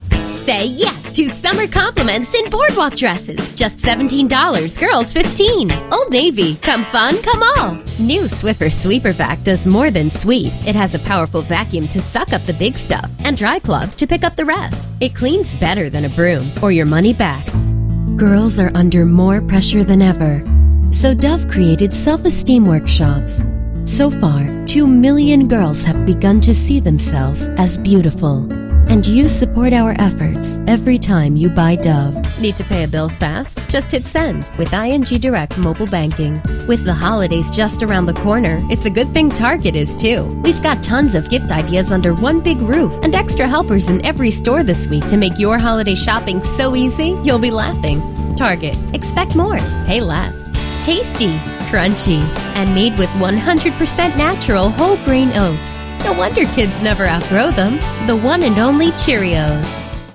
Youthful, energetic, fun!
Kein Dialekt
Sprechprobe: Werbung (Muttersprache):